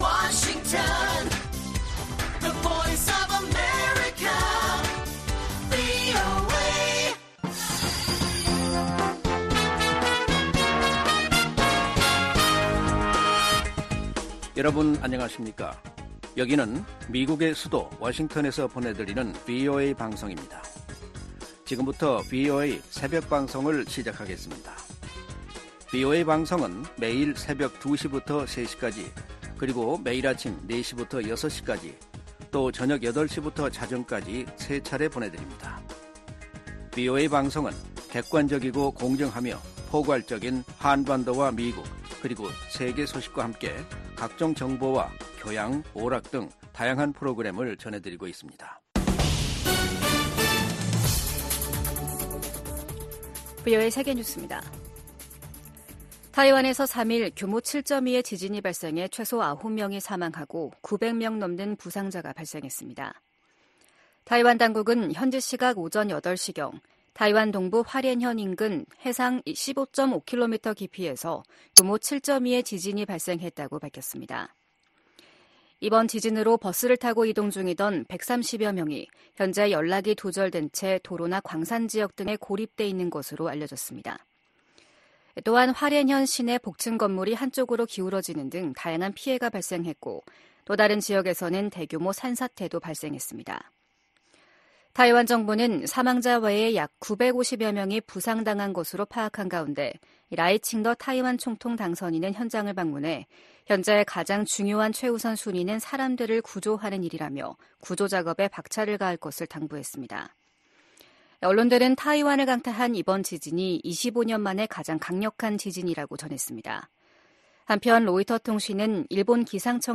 VOA 한국어 '출발 뉴스 쇼', 2024년 4월 4일 방송입니다. 북한이 신형 중장거리 고체연료 극초음속 탄도미사일 시험발사에 성공했다고 대외 관영매체들이 보도했습니다. 북한이 보름 만에 미사일 도발을 재개한 데 대해 유엔은 국제법 위반이라고 지적했습니다.